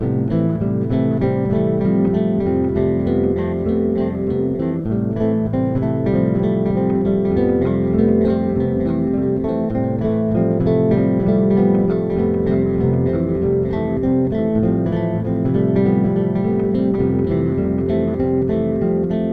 Luma 7-4 (Loop).mp3